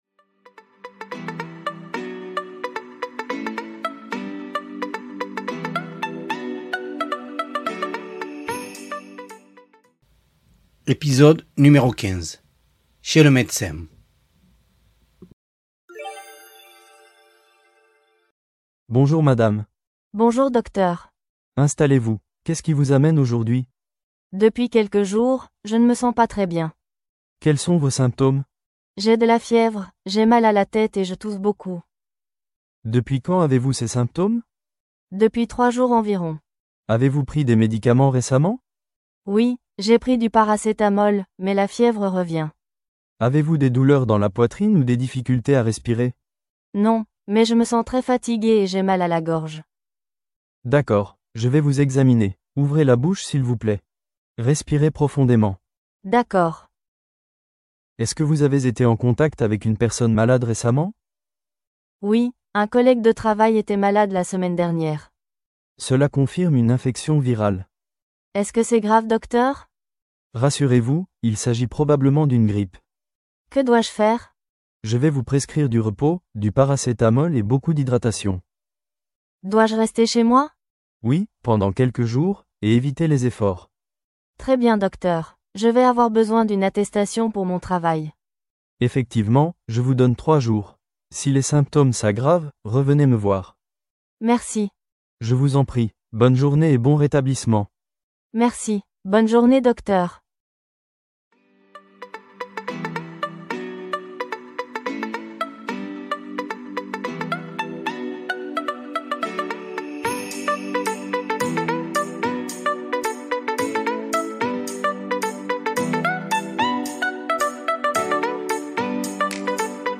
Voici un dialogue pour les élèves de niveau débutant. Avec cet épisode, vous verrez le vocabulaire relatif à une consultation médicale.
015-Podcast-dialogues-Chez-le-medecin.mp3